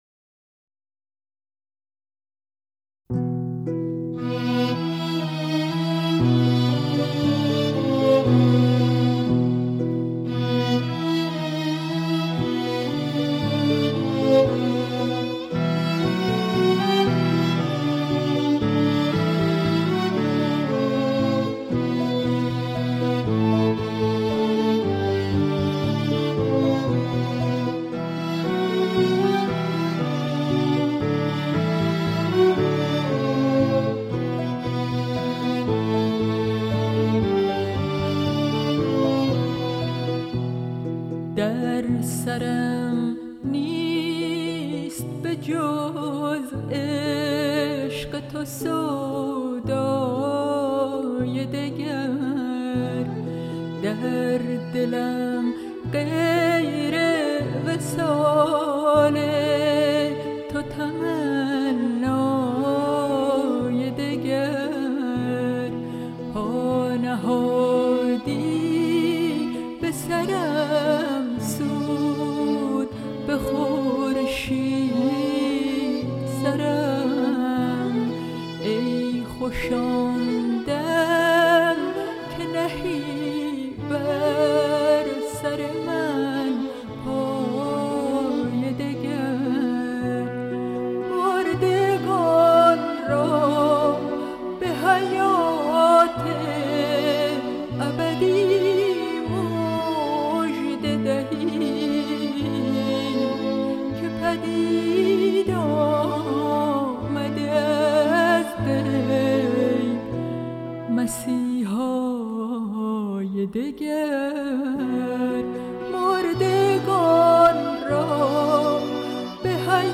سرود - شماره 7 | تعالیم و عقاید آئین بهائی
So magical ... It's amazing to hear her voice so beautiful